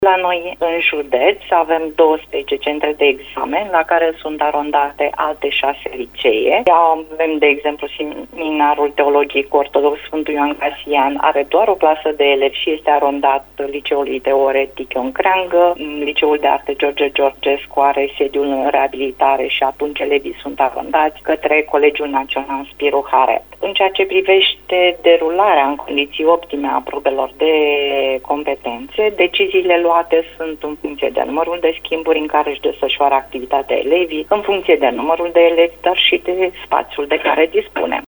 Recent, și inspectorul general al Inspectoratului Școlar Județean Tulcea, Viorica Pavel, a vorbit la Radio Constanța despre aceste schimbări, precum și despre modalitatea în care se vor derula probele de competențe, în contextul desfășurării normale a orelor de curs pentru restul elevilor: